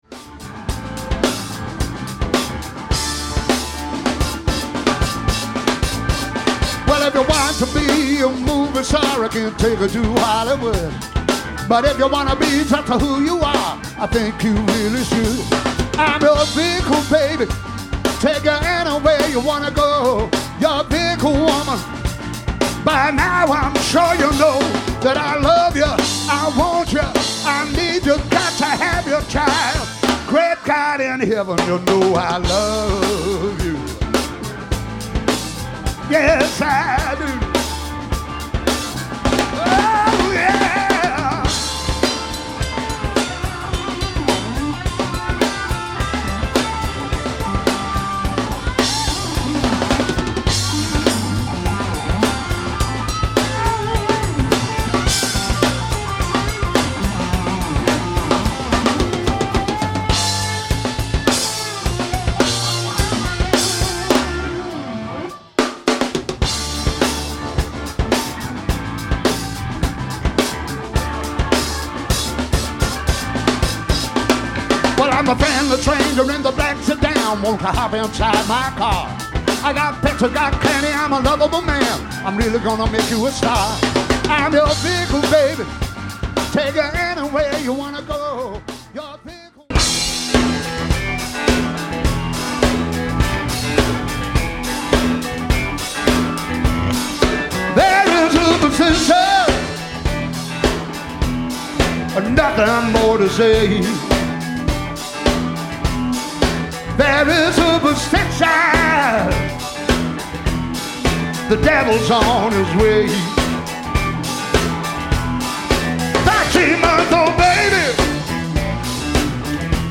all songs recorded live 2009